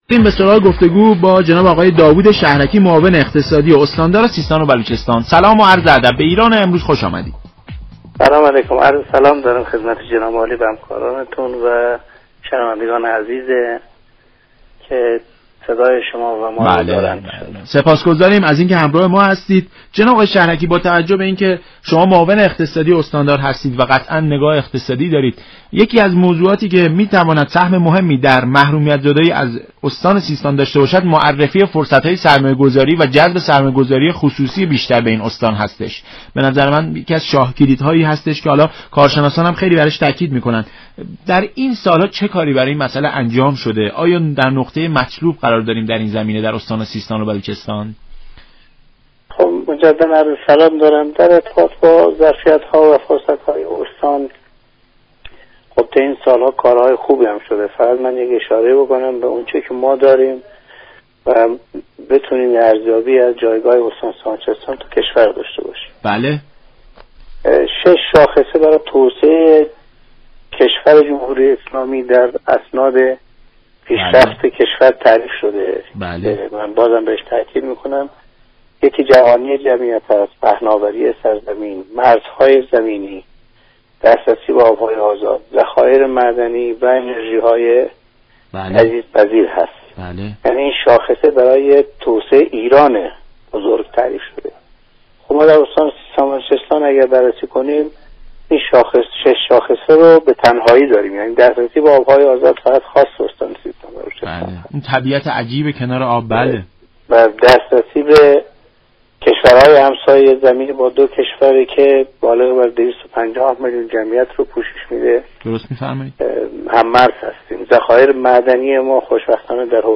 به گزارش شبكه رادیویی ایران؛ داوود شهركی معاون اقتصادی استاندار سیستان و بلوچستان در برنامه ایران‌امروز به ظرفیت‌های استان پرداخت و گفت: استان سیستان و بلوچستان شش شاخصه پیشرفت (جوانی جمعیت، پهناوری سرزمین، مرزهای زمینی، دسترسی به آب‌های آزاد، انرژی های تجدیدپذیر و دخایر معدنی) را در خود دارد.